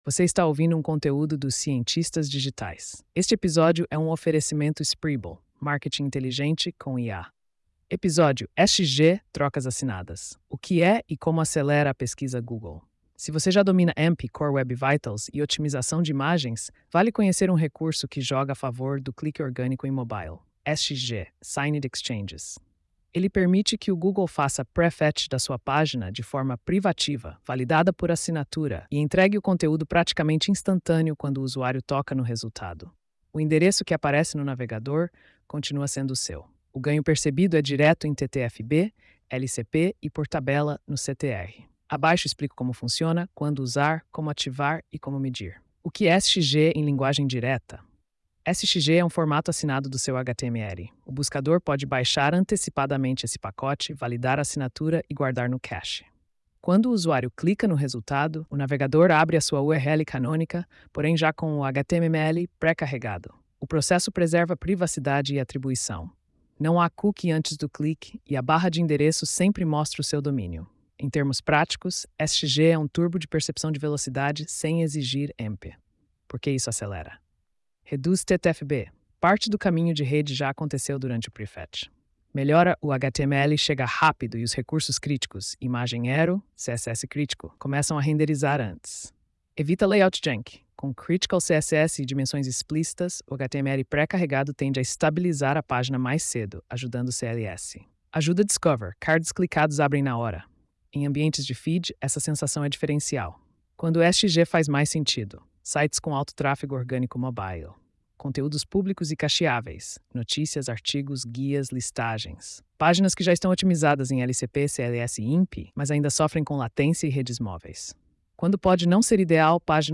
post-4305-tts.mp3